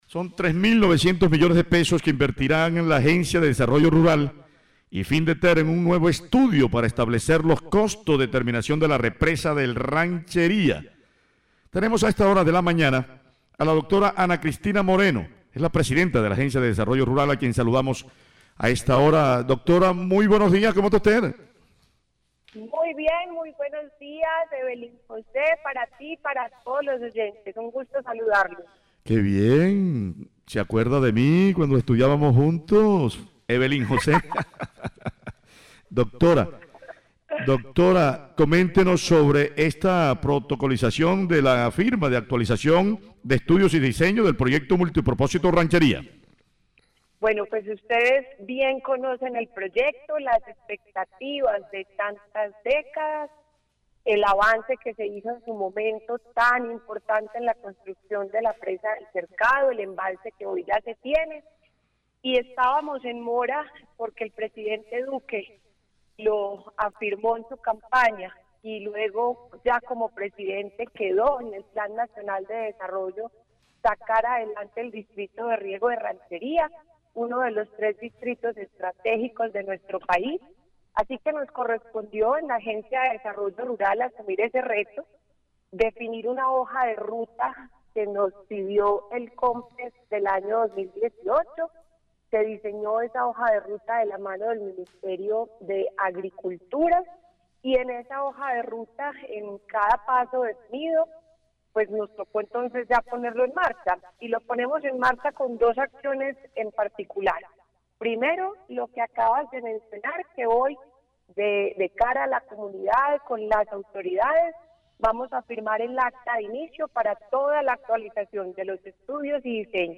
Voz-Ana-Cristina-–-Presidencia-Agencia-Rural.mp3